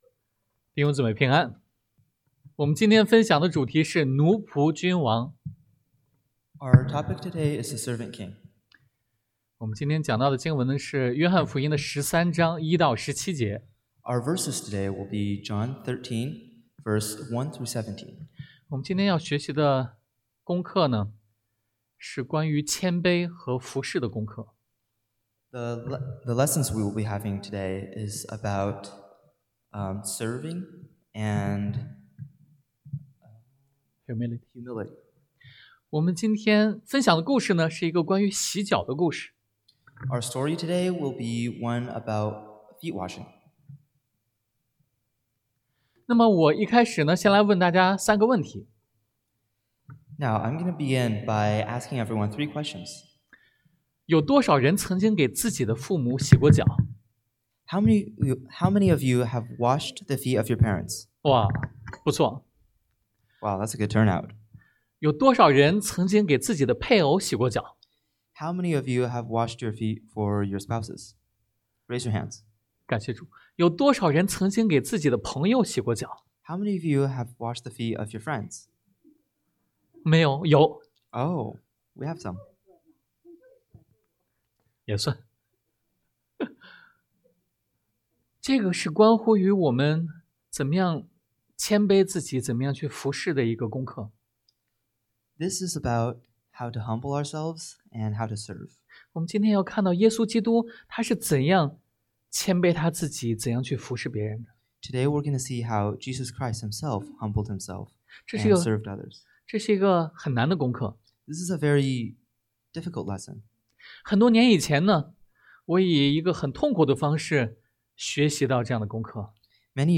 Passage: 约翰福音 John 13:1-17 Service Type: Sunday AM